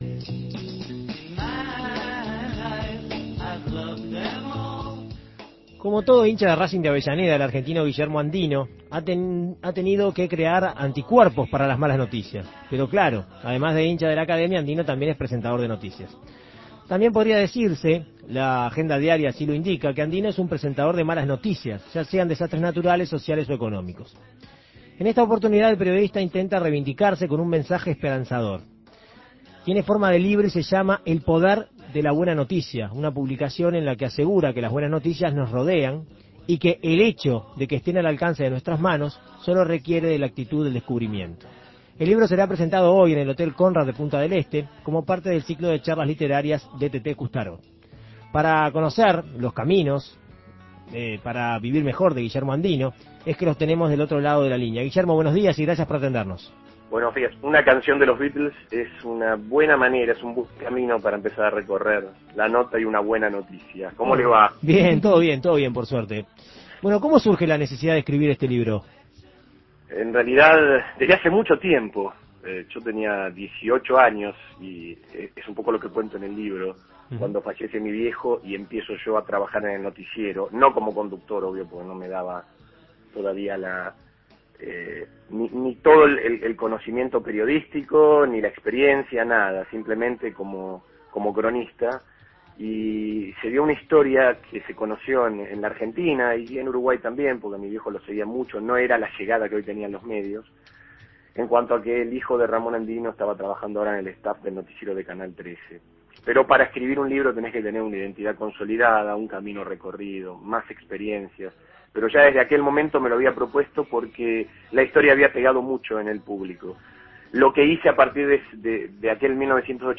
En Perspectiva Segunda Mañana dialogó con el periodista para conocer detalles de la propuesta.